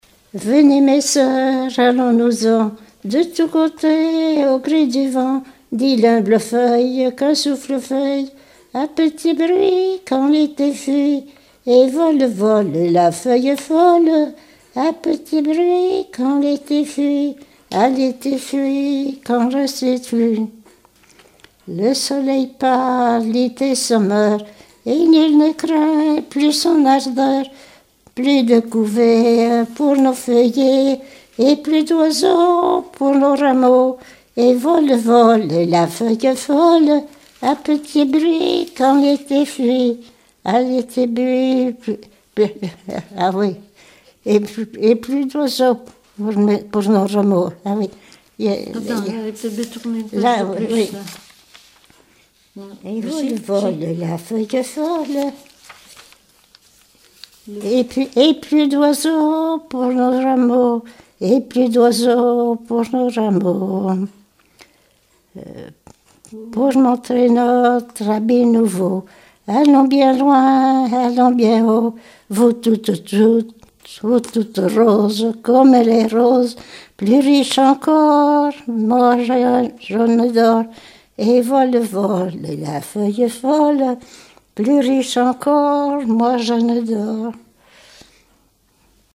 Mémoires et Patrimoines vivants - RaddO est une base de données d'archives iconographiques et sonores.
Genre strophique
chansons d'écoles et populaires
Pièce musicale inédite